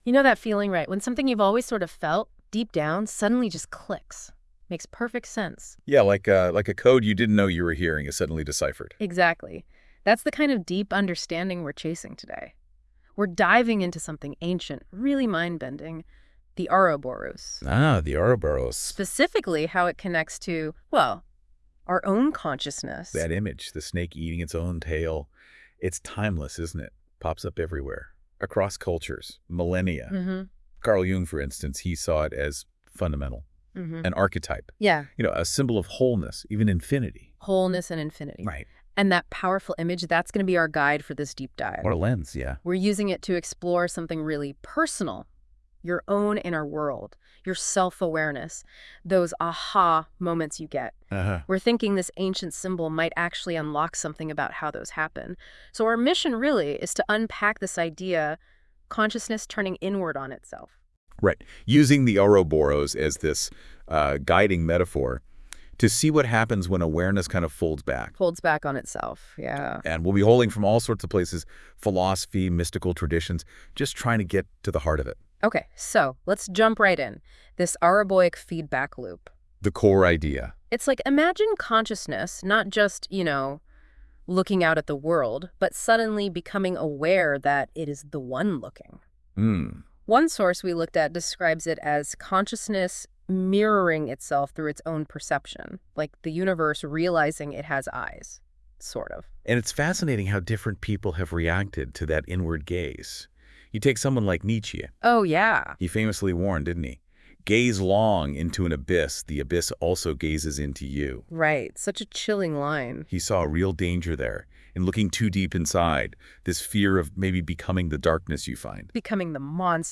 Click for an example of a singular feedback created sound loop.
OuroboricFeedback.wav